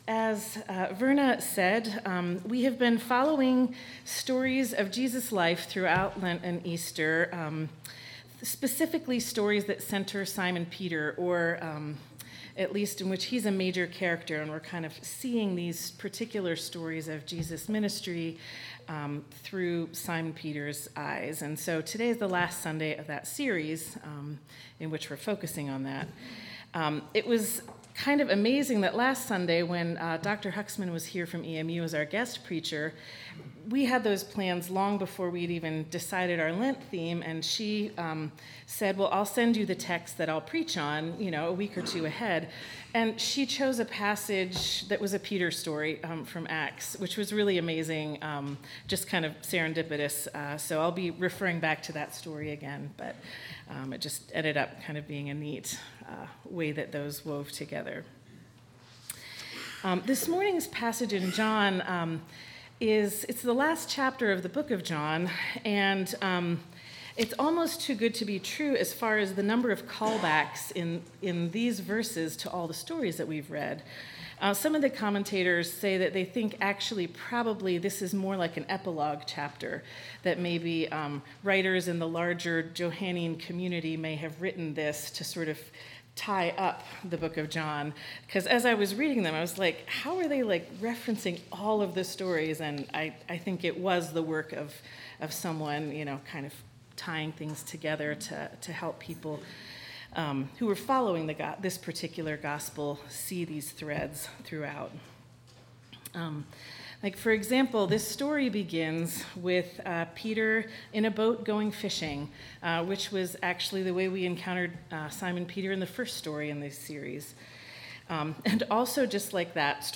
4/14/24 Sermon